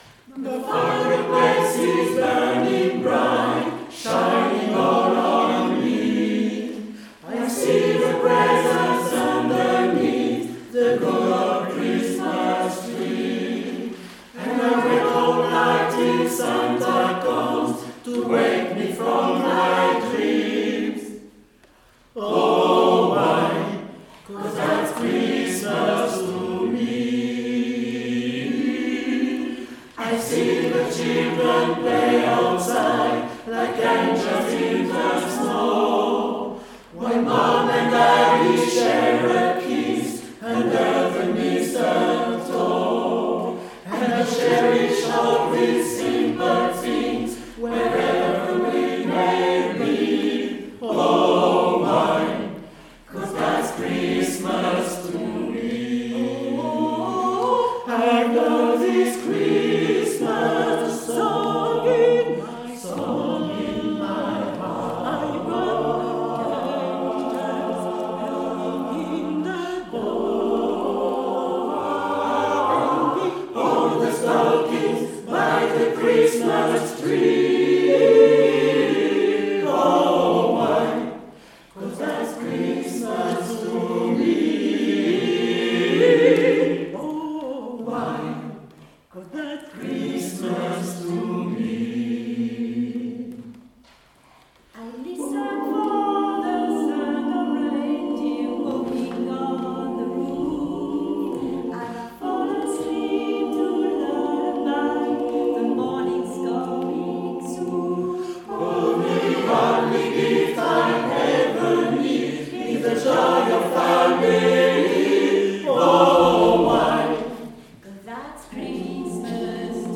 C'était il y a quelques jours, on a adoré chanter ça
On est amateurs,